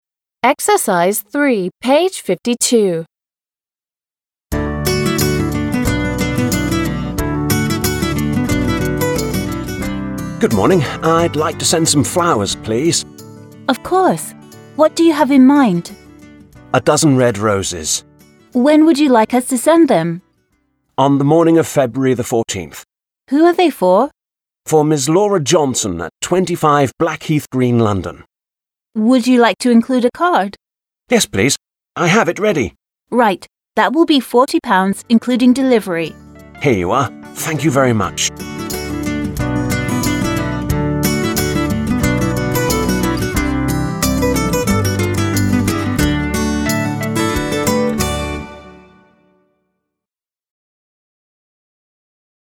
These sentences are from the following dialogue between a shop assistant and a customer.